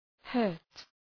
hurt Προφορά
{hɜ:rt}